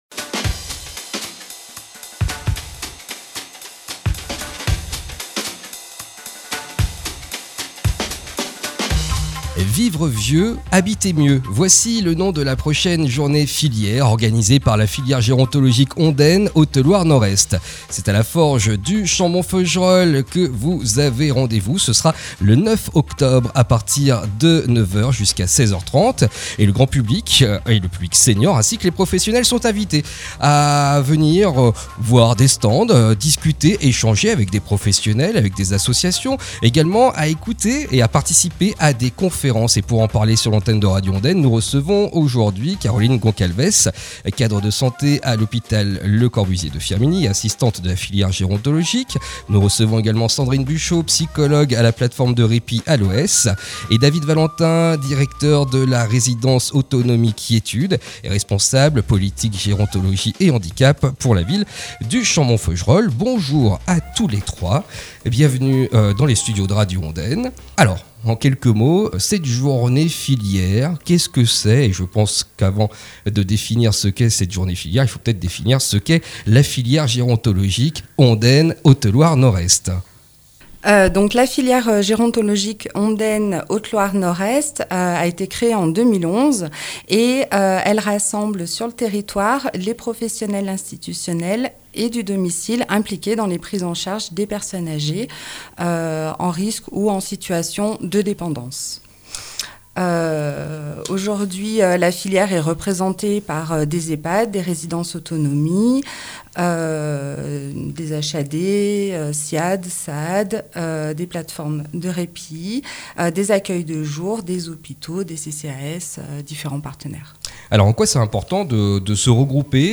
Pour expliquer cette journée filière, nous recevons aujourd’hui, à 11h30, sur Radio Ondaine